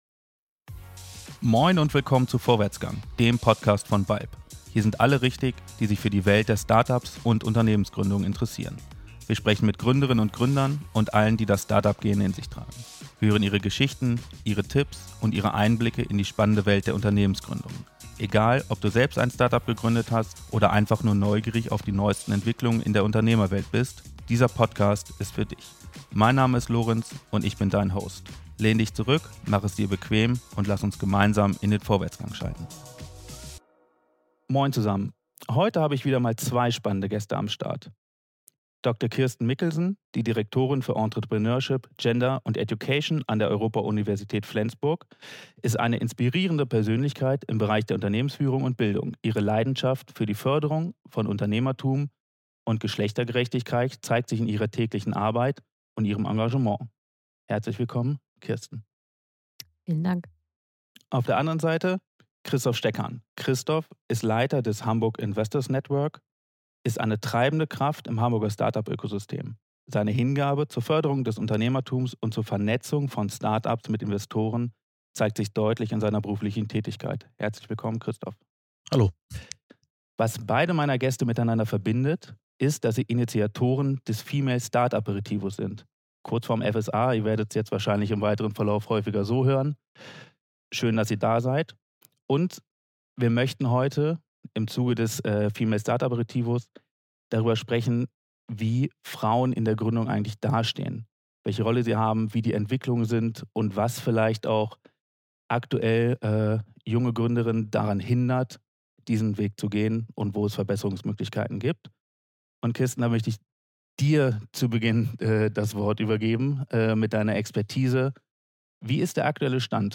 Wir sprechen in dieser Folge mit den treibenden Kräften hinter dem Wettbewerb.